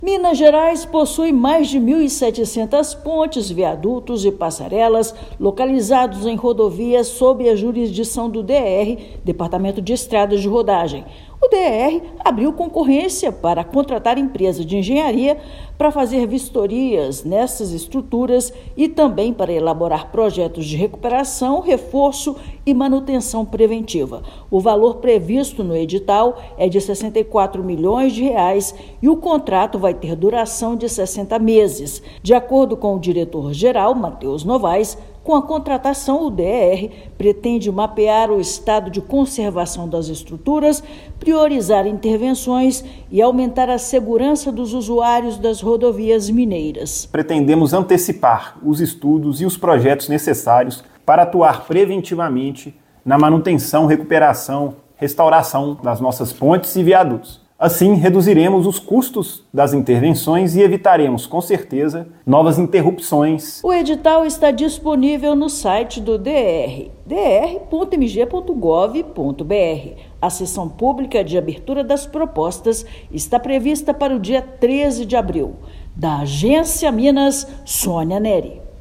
Investimento de R$ 64 milhões vai permitir o monitoramento de mais de 1.700 estruturas. Ouça a matéria de rádio.